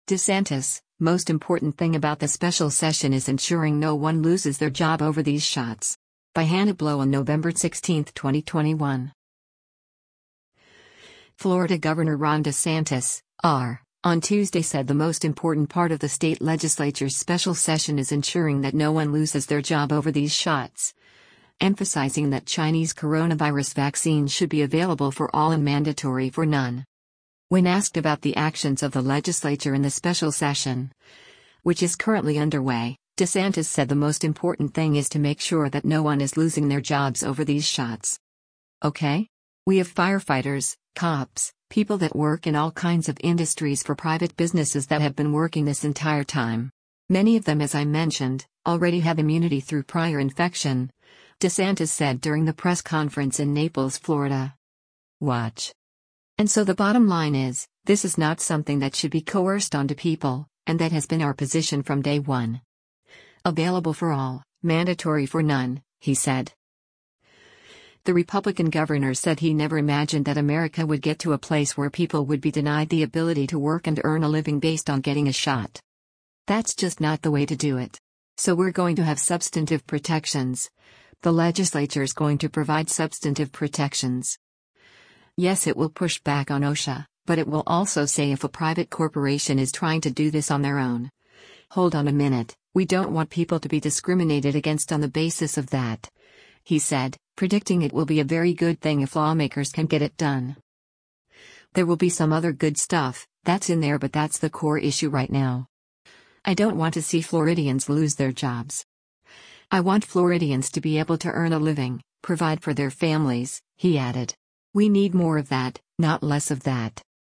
“Ok? We have firefighters, cops, people that work in all kinds of industries for private businesses that have been working this entire time. Many of them as I mentioned, already have immunity through prior infection,” DeSantis said during the press conference in Naples, Florida.